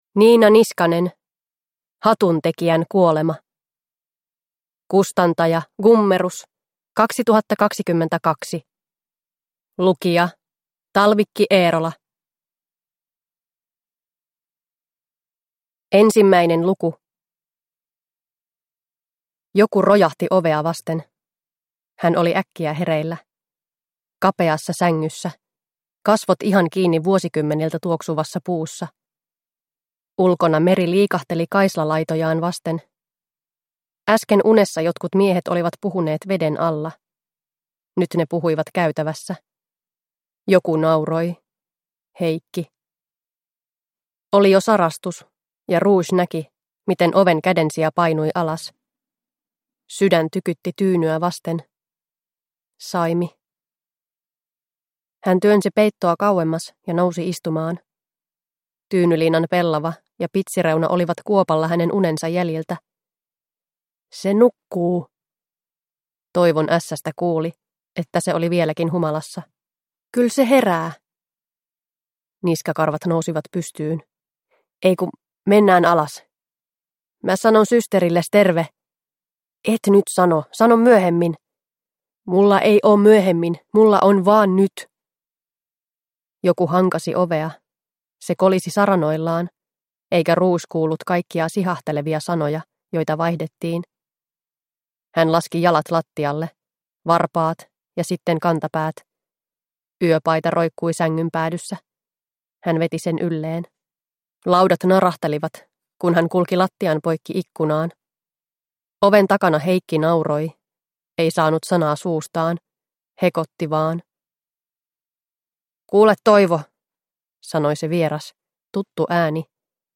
Hatuntekijän kuolema – Ljudbok – Laddas ner